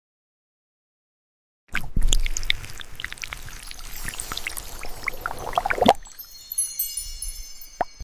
A fountain pen touches it with a crisp “scratch”, but instead of ink, glowing liquid letters flow out, making a soft “drip-drip” as they spread. Each stroke produces a faint harmonic chime, as if the letters are singing themselves into existence.